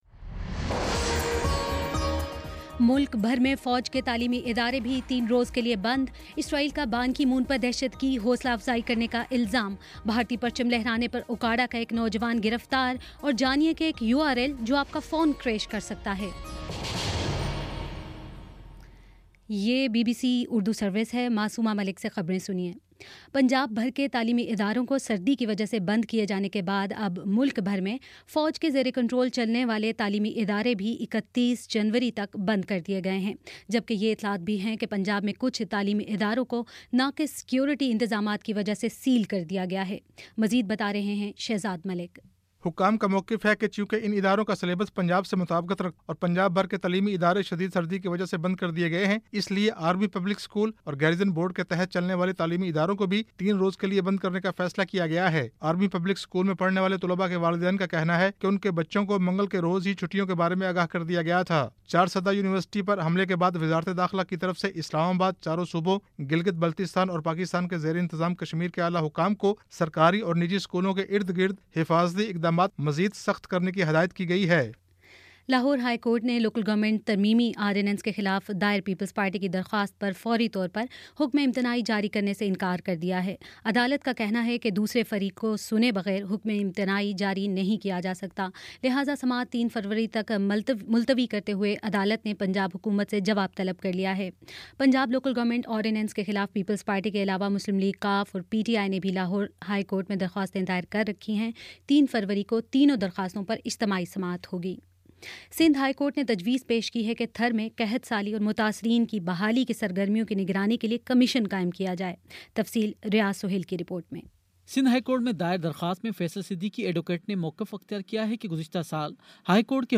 جنوری 27 : شام پانچ بجے کا نیوز بُلیٹن